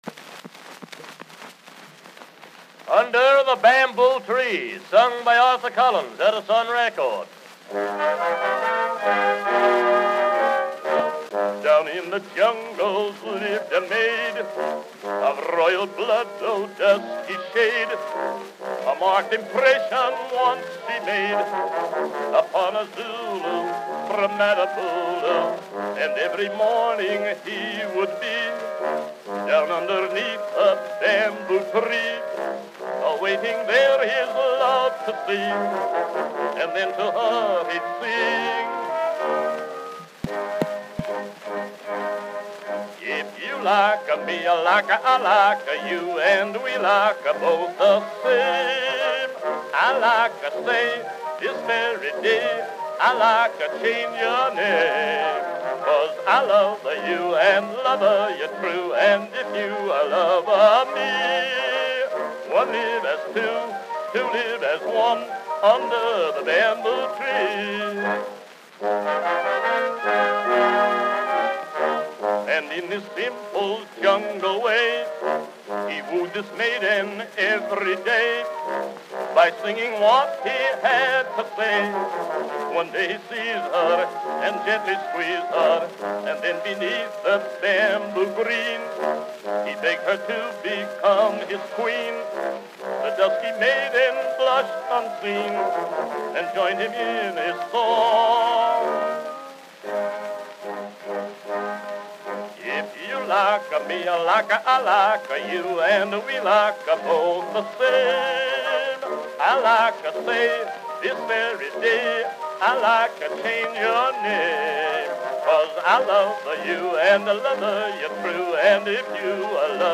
Arthur Collins.
Vocal selection.
Popular music—1901-1910.